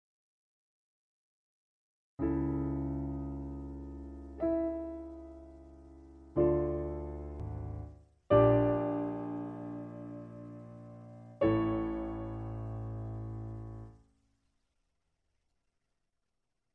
Half step lower. Piano Accompaniment